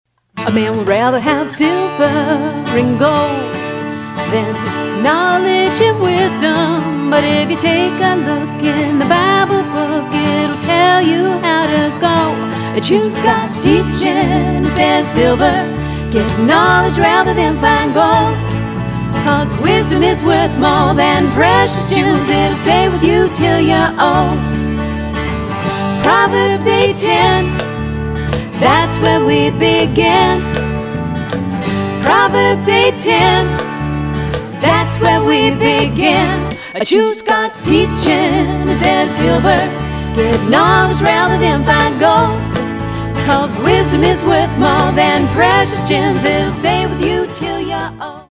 eclectic, contemporary Bible verse songs